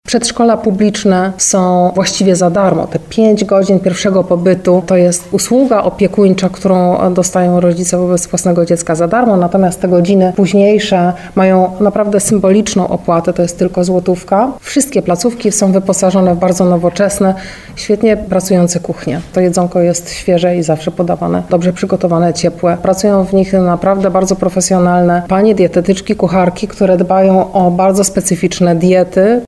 Wiceprezydent Tarnowa Agnieszka Kawa jeszcze przed rekrutacją zwracała uwagę, że oprócz wykwalifikowanej kadry przedszkolanek i osób do pomocy, koszty pobytu dziecka w miejskim przedszkolu są bardzo niskie.